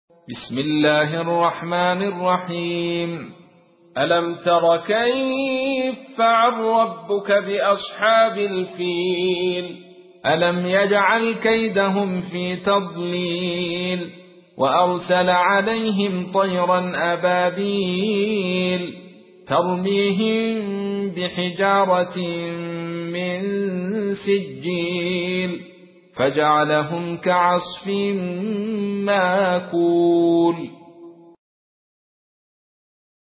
تحميل : 105. سورة الفيل / القارئ عبد الرشيد صوفي / القرآن الكريم / موقع يا حسين